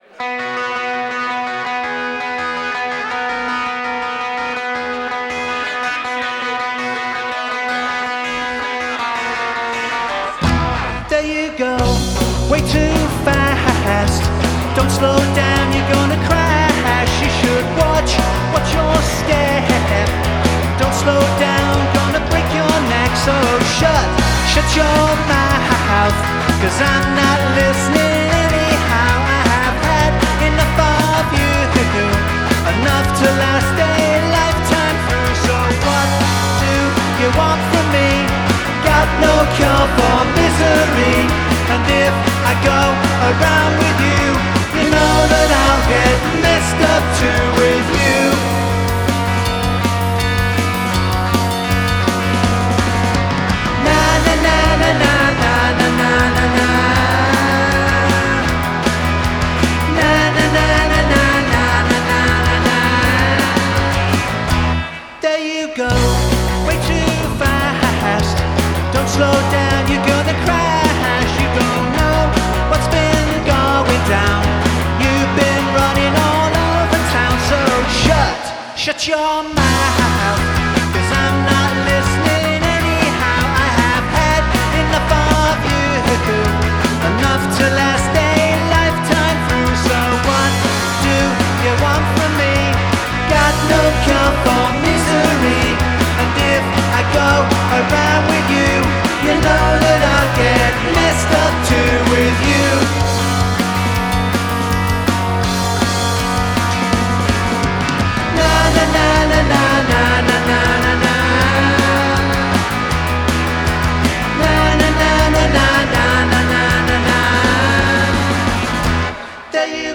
live at the Eagle Dec 24